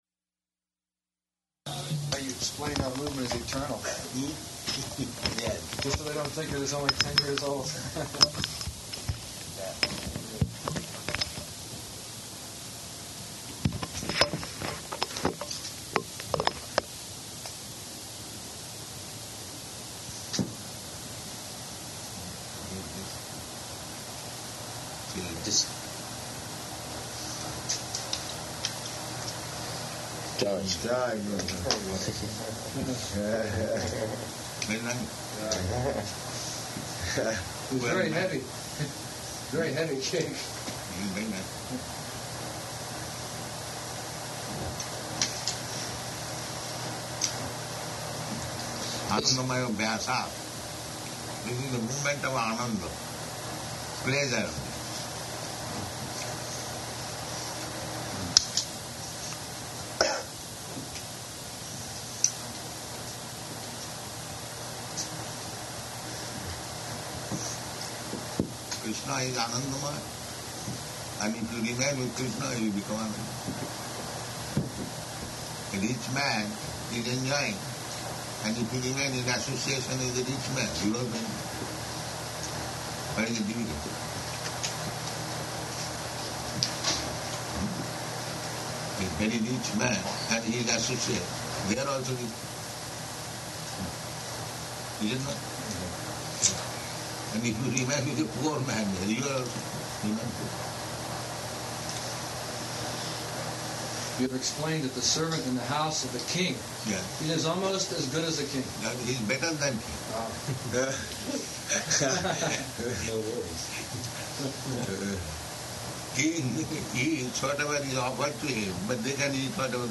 Room Conversation
-- Type: Conversation Dated: July 6th 1976 Location: Washington D.C. Audio file